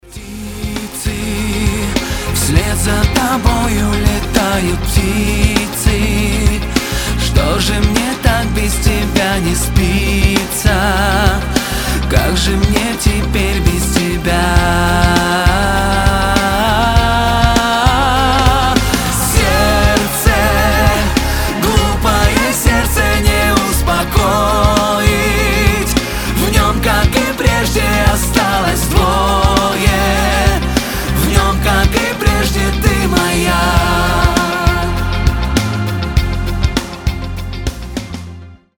• Качество: 320, Stereo
красивые
грустные